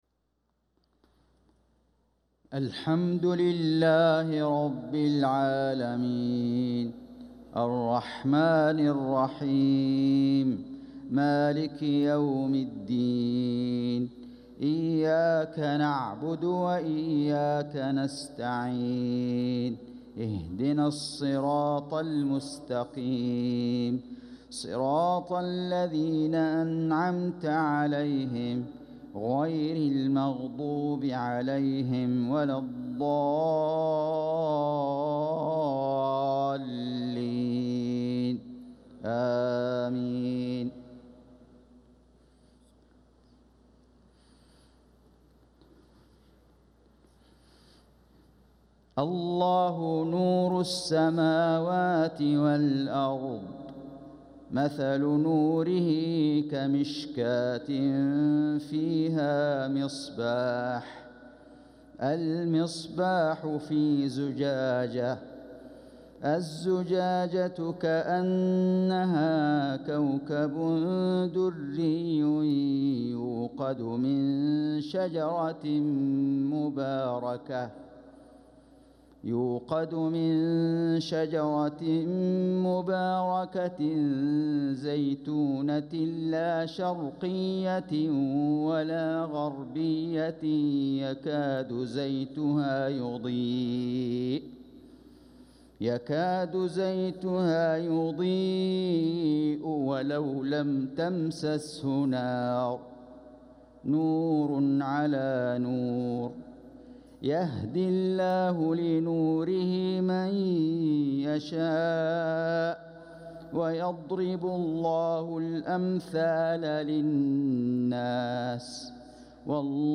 صلاة العشاء للقارئ فيصل غزاوي 10 صفر 1446 هـ
تِلَاوَات الْحَرَمَيْن .